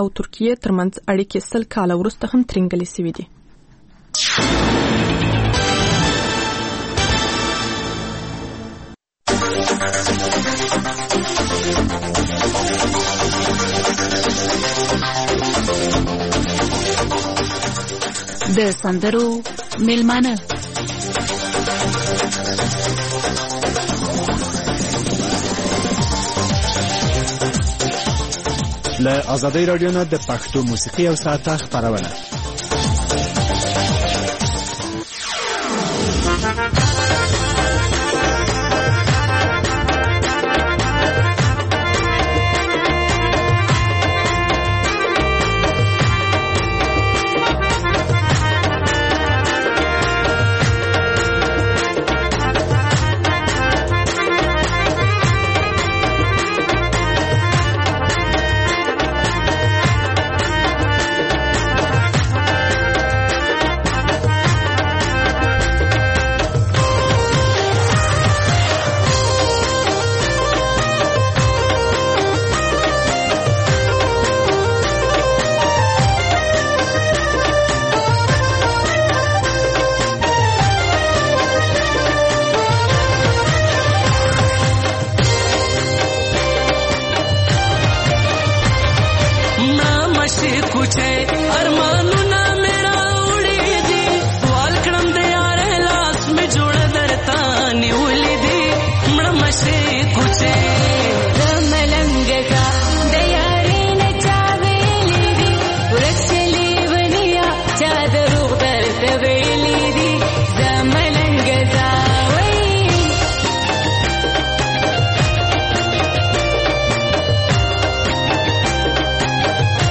د سندرو مېلمانه ( موسیقي)